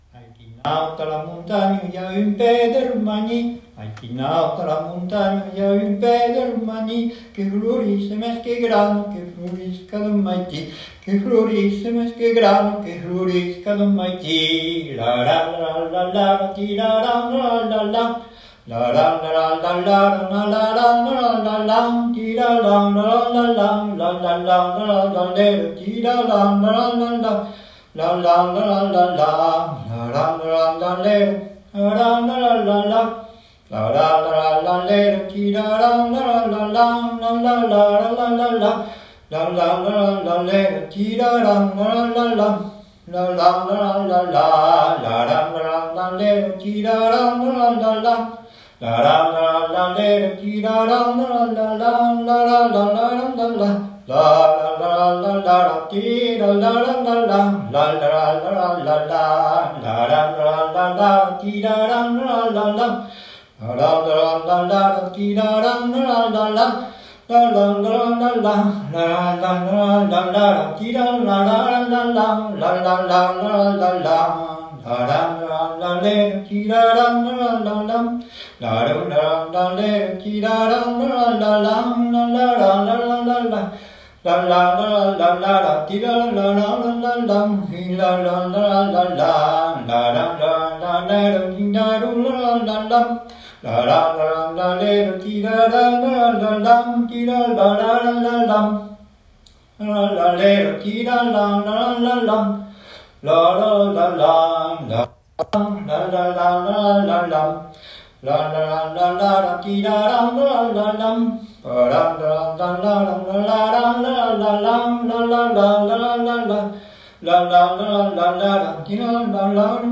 Le chant au Tralala
Entendu lors des veillées, le « chant au Tralala » est un chant sans paroles qu’on pratique dans plusieurs régions, entre autres l’Auvergne, le Limousin, les Pyrénées (où il était bien plus courant de l’entendre que d’entendre jouer du hautbois).
Le Tralala est créé sur des onomatopées, quelques syllabes ou des allitérations et parfois il imitera des instruments.